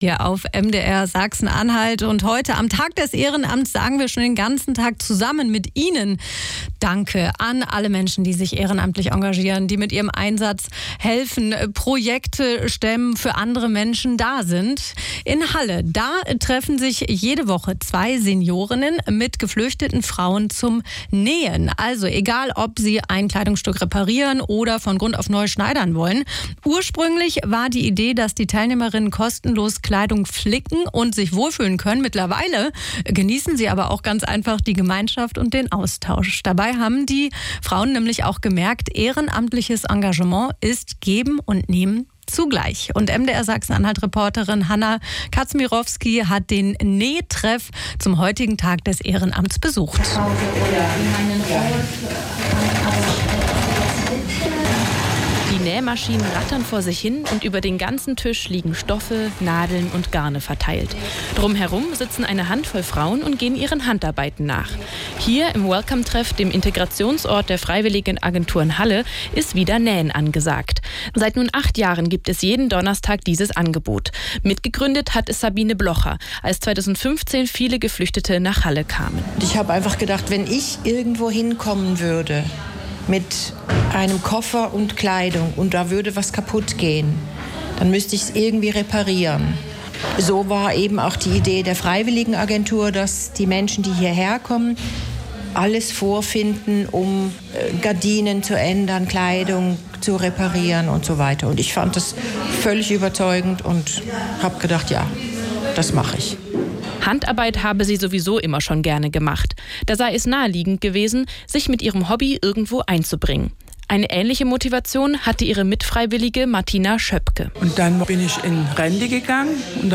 mitschnitt_bme_naeh_treff.mp3